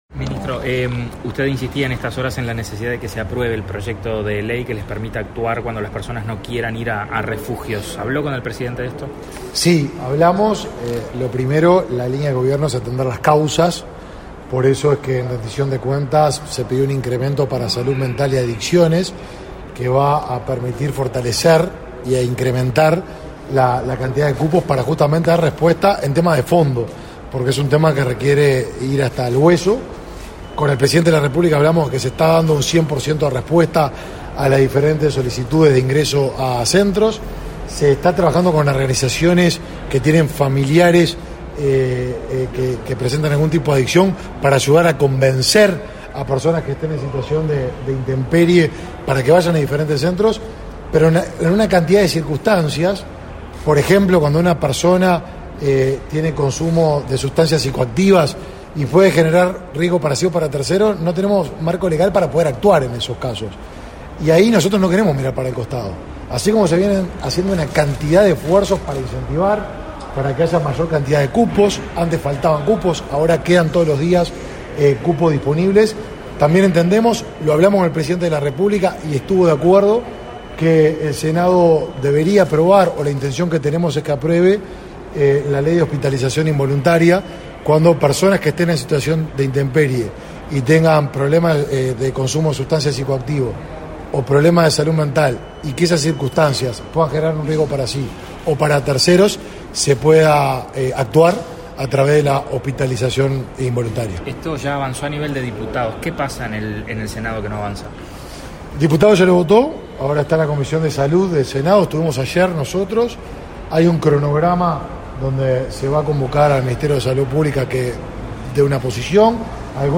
Declaraciones a la prensa del ministro de Desarrollo Social, Martín Lema
Declaraciones a la prensa del ministro de Desarrollo Social, Martín Lema 09/08/2023 Compartir Facebook X Copiar enlace WhatsApp LinkedIn Tras participar en la reunión de acuerdos con el presidente de la República, Luis Lacalle Pou, este 9 de agosto, el ministro de Desarrollo Social, Martín Lema, realizó declaraciones a la prensa.